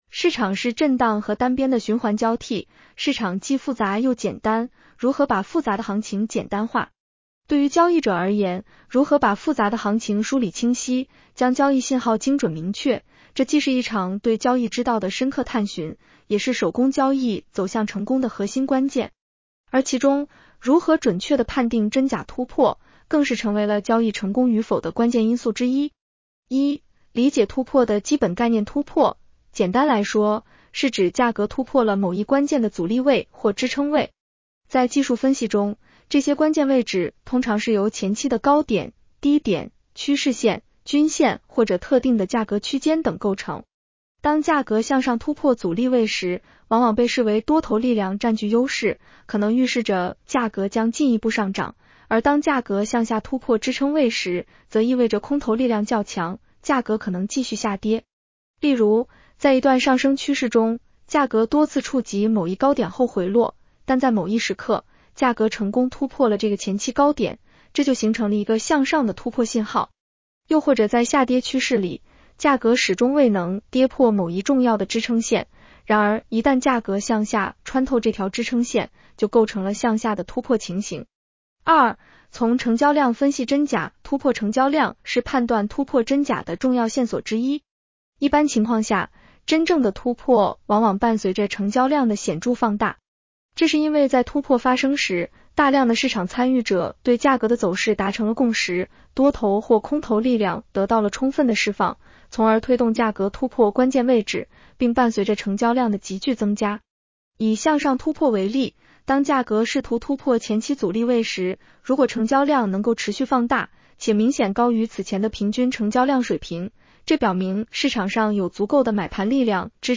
女声普通话版 下载mp3 市场是震荡和单边的循环交替，市场既复杂又简单，如何把复杂的行情简单化。